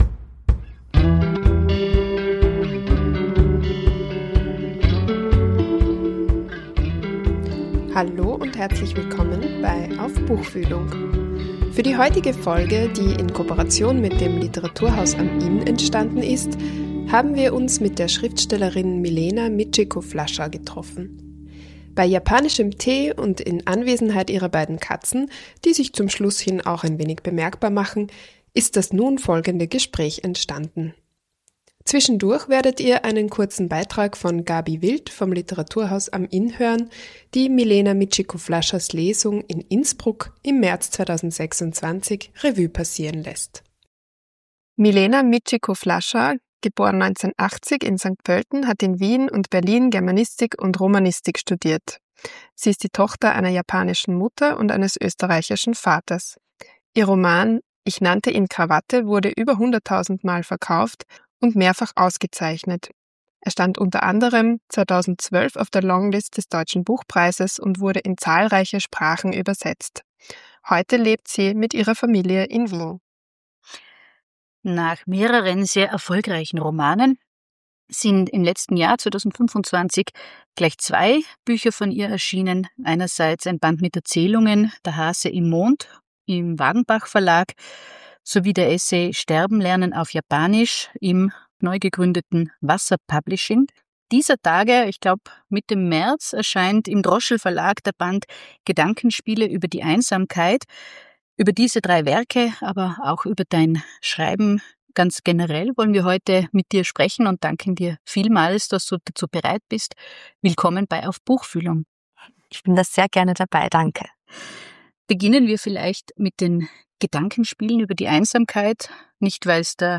Ihre Bücher verhandeln auf literarisch raffinierte Weise Themen wie Vergänglichkeit und Tod, Einsamkeit und unerfüllte Sehnsüchte. Bei einer Tasse japanischem Tee sprechen wir mit der Autorin über ihre neuesten Bücher - und das sind gleich drei: “Der Hase im Mond” versammelt surreal-fantastische “japanische Geschichten”, in denen nicht nur eine Füchsin und mehrere Doppelgänger*innen eine Rolle spielen.